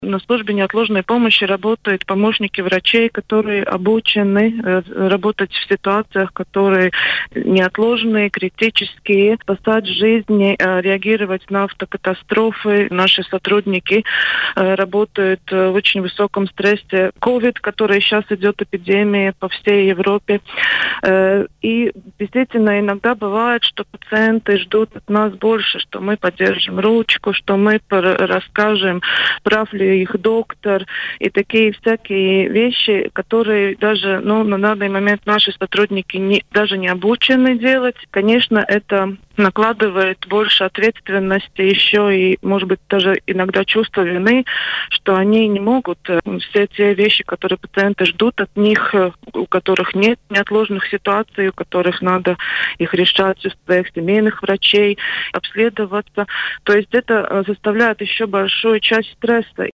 Об этом в эфире радио Baltkom заявила глава Службы неотложной медицинской помощи Лиене Ципуле.